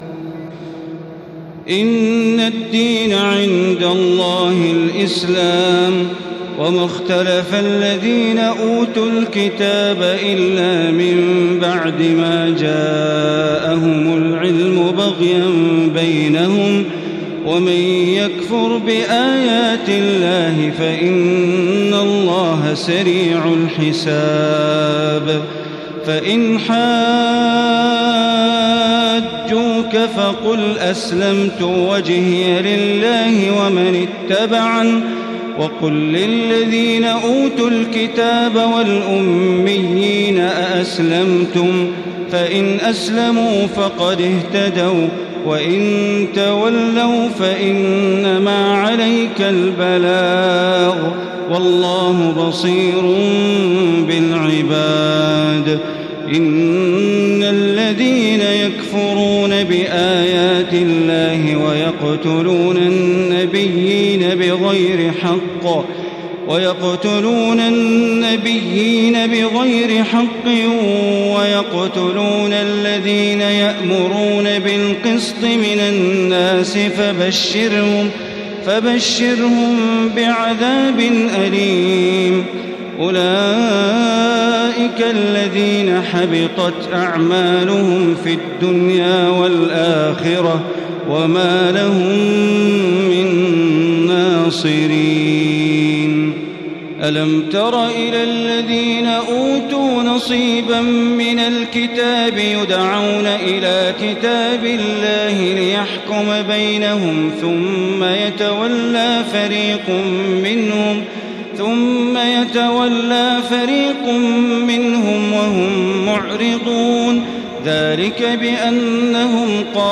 تراويح الليلة الثالثة رمضان 1435هـ من سورة آل عمران (19-92) Taraweeh 3st night Ramadan 1435 H from Surah Aal-i-Imraan > تراويح الحرم المكي عام 1435 🕋 > التراويح - تلاوات الحرمين